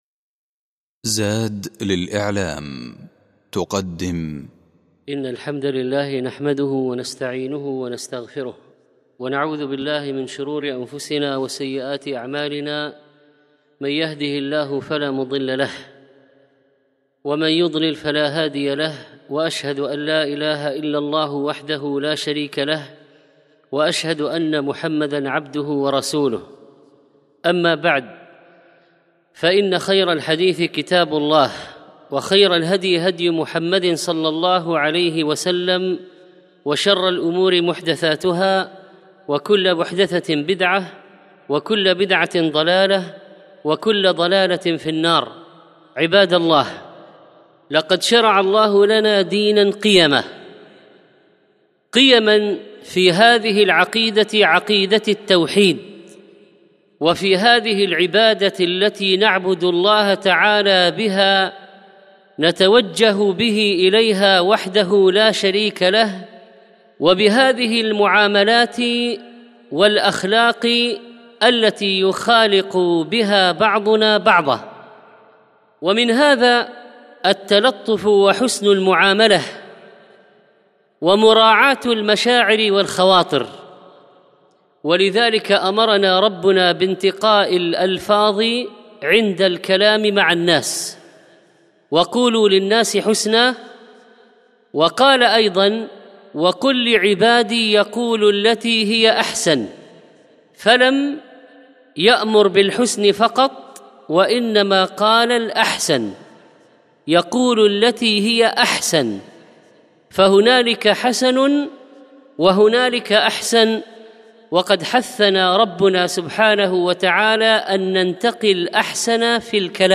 الخطبة الأولى مراعاة المشاعر من القيم التي حثت الشريعة عليها